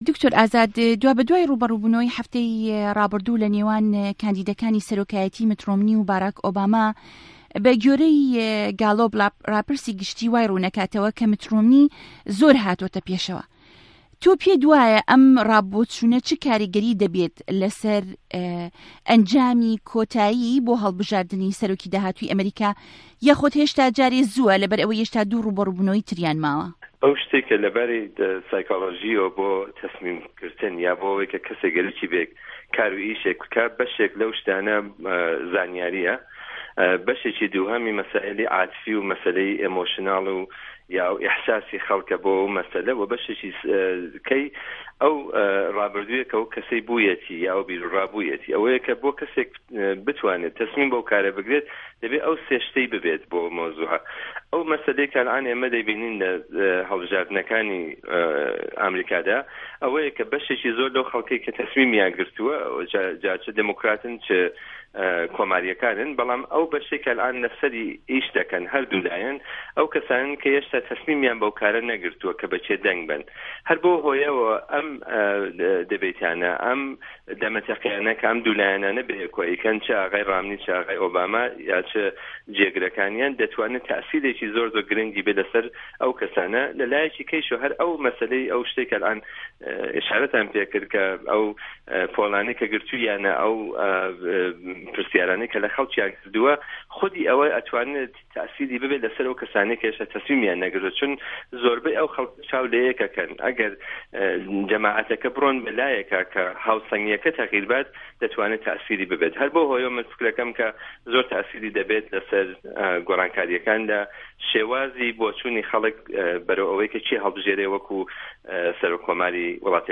گفتوکۆ